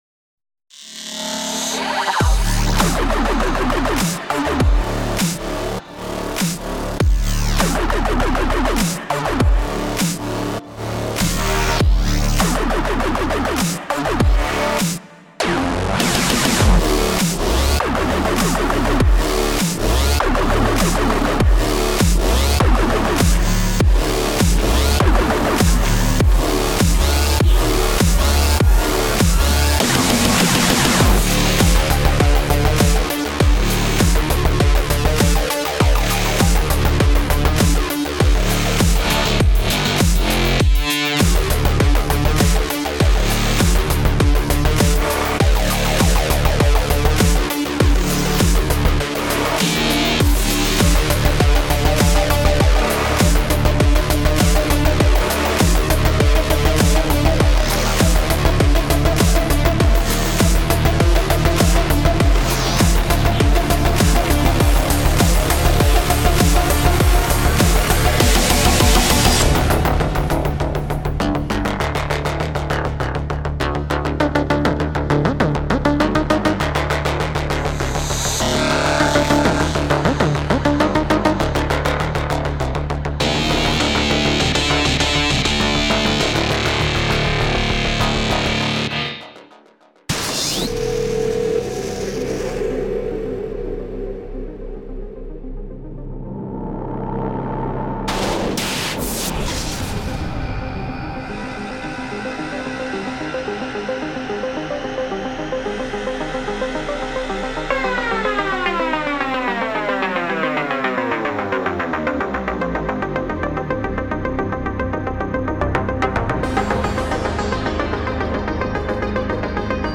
• 43 Arps
• 23 Basses
• 10 Strings
• 8 Pads